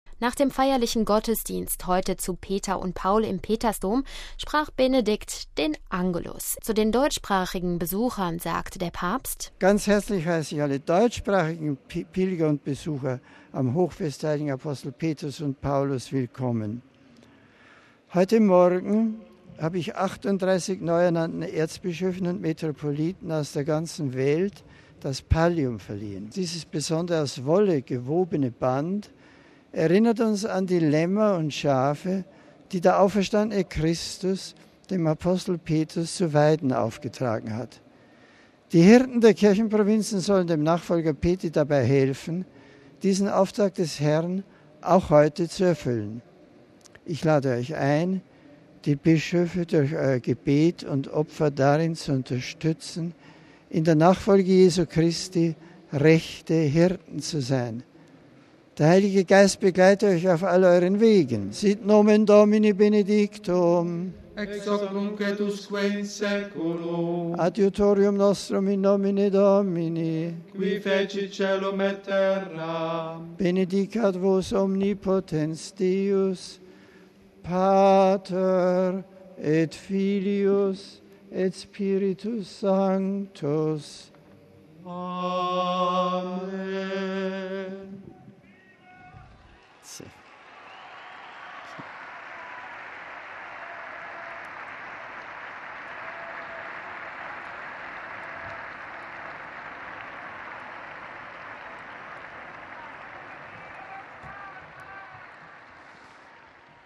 Er wandte sich an die vielen Gläubigen, die auf dem Petersplatz versammelt waren. Zu den deutschsprachigen Besuchern sagte er: